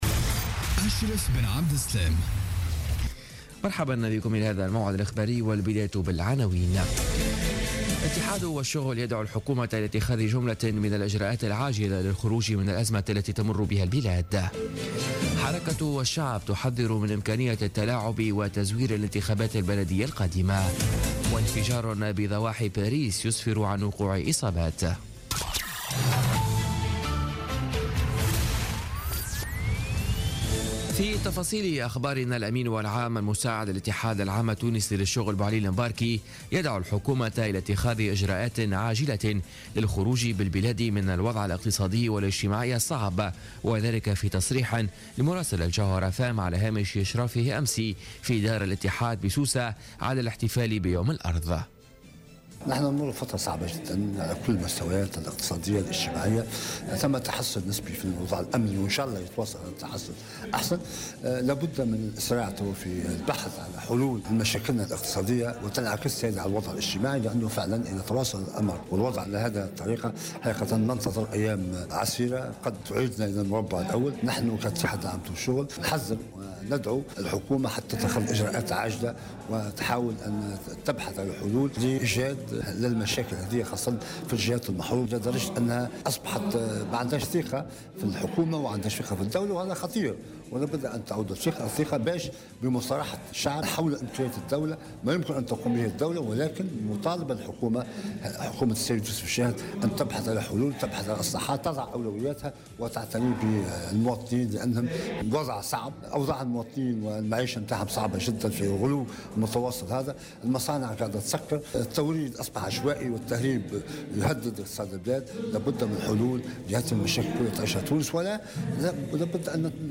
نشرة أخبار منتصف الليل ليوم الأحد 2 أفريل 2017